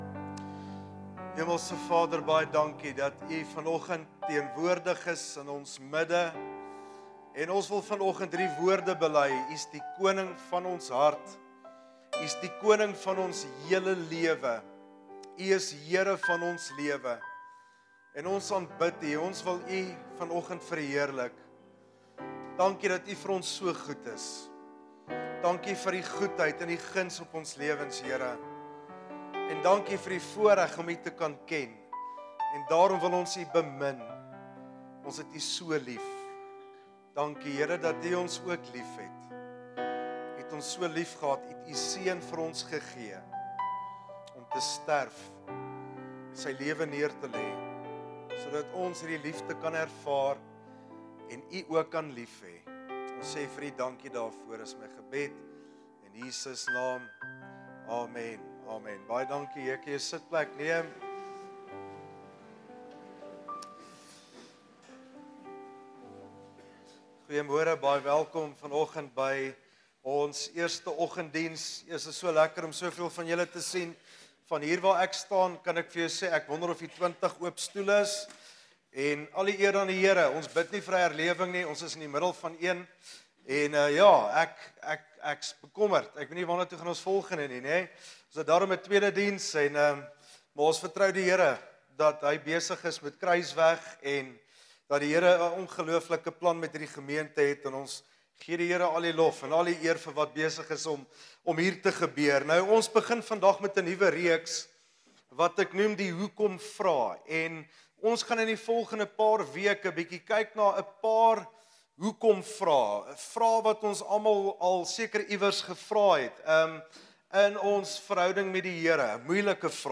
Sermons Archive - Page 27 of 59 - KruisWeg Gemeente